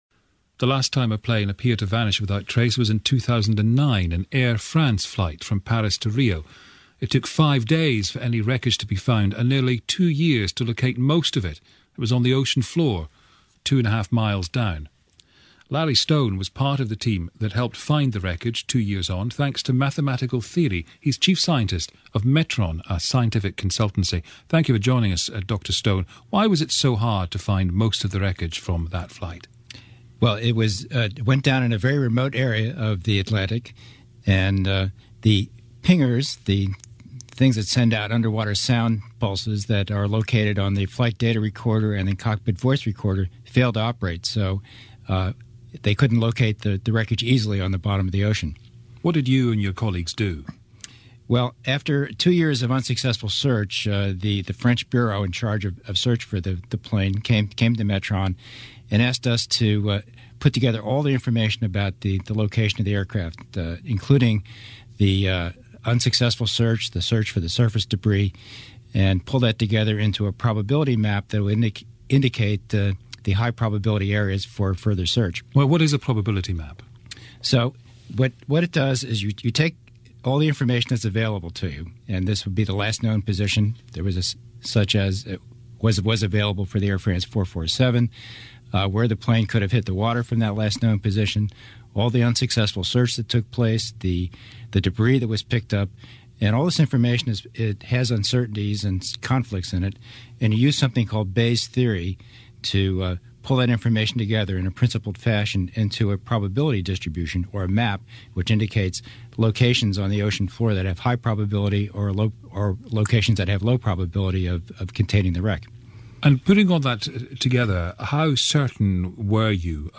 BBC - Radio - Interview 1